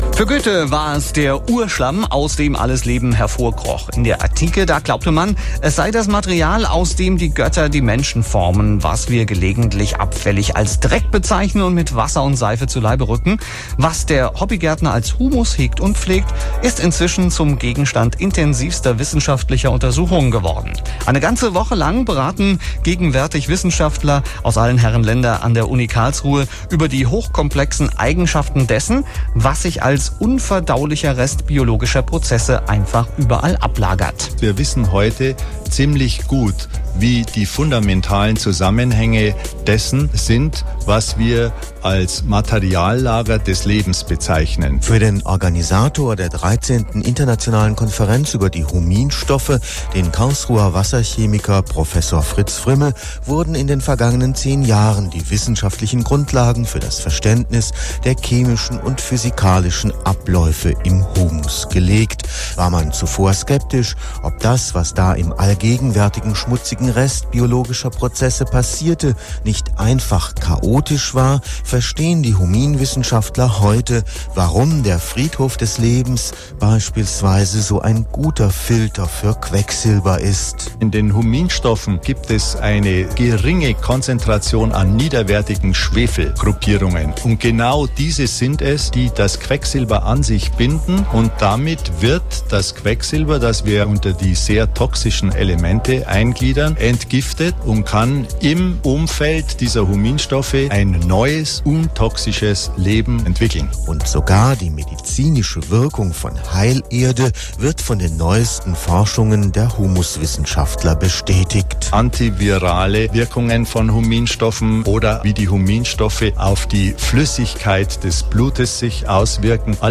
Interviewter